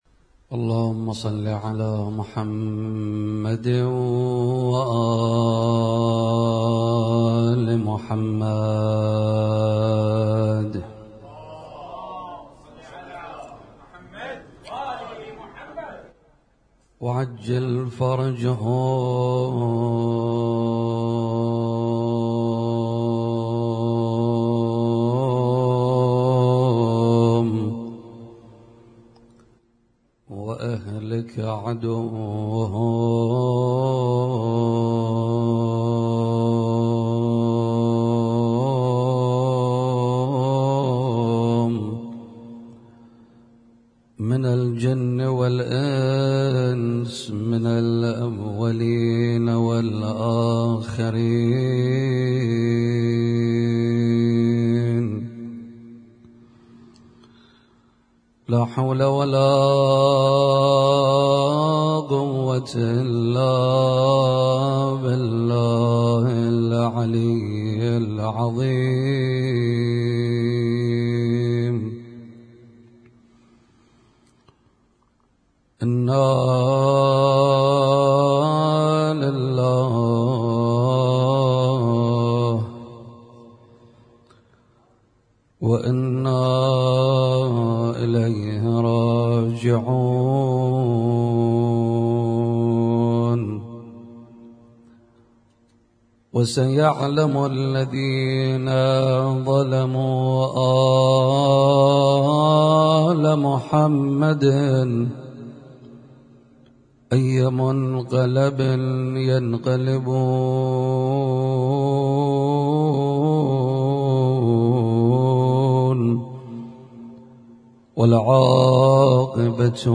Husainyt Alnoor Rumaithiya Kuwait
القارئ: الرادود